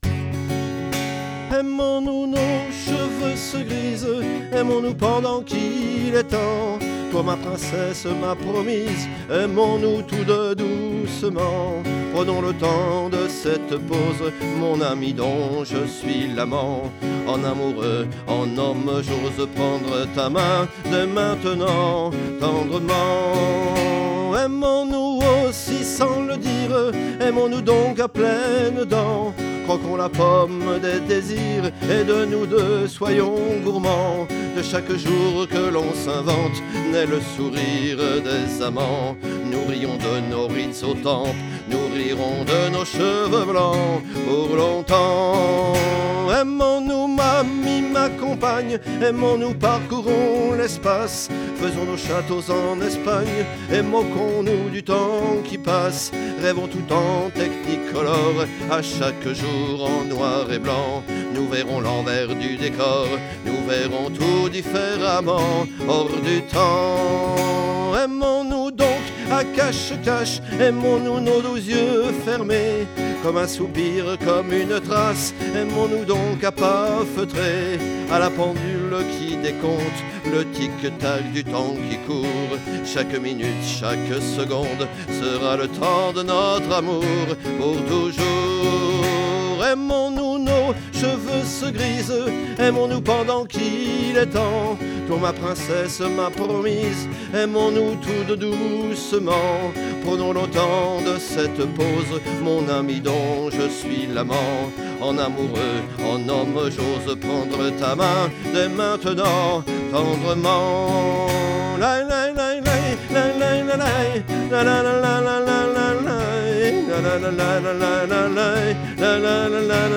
chant, guitare